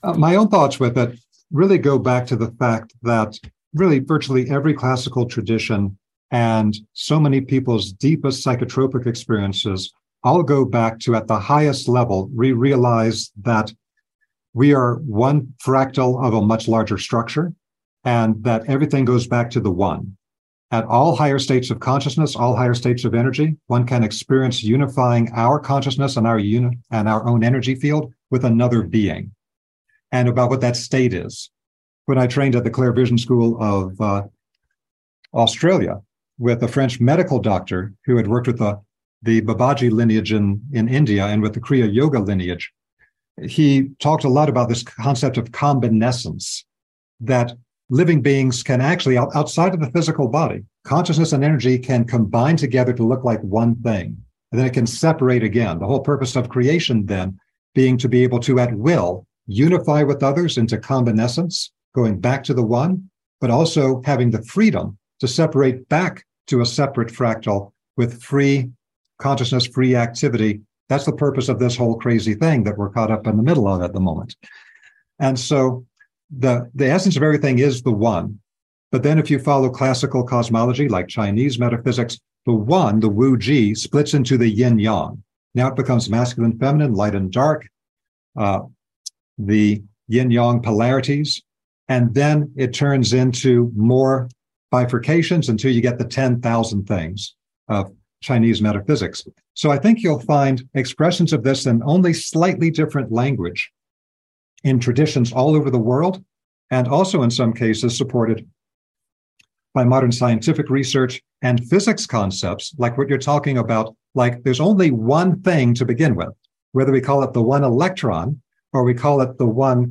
engages in a profound dialogue with a DMT Scientist.